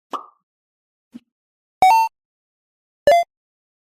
Slot Machine
Slot Machine is a free sfx sound effect available for download in MP3 format.
541_slot_machine.mp3